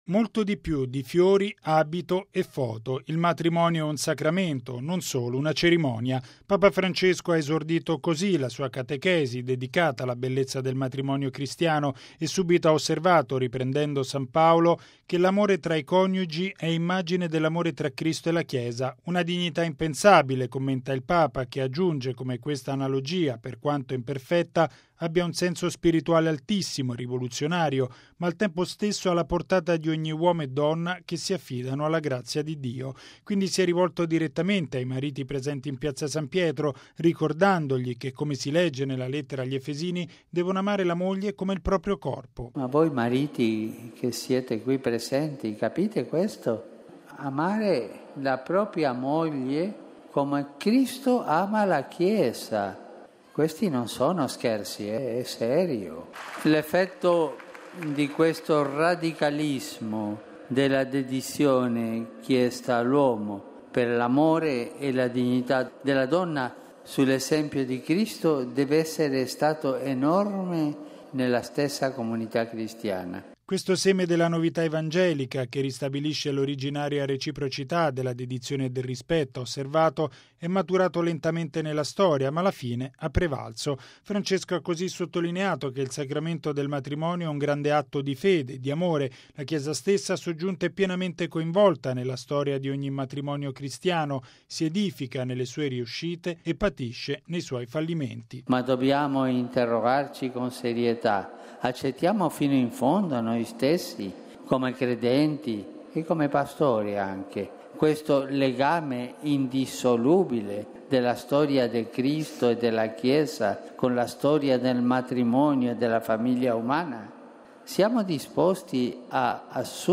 Il Pontefice, parlando ad una Piazza San Pietro gremita di fedeli nonostante il caldo, ha sottolineato che la famiglia è corresponsabile della vita familiare, delle sue riuscite come dei suoi fallimenti.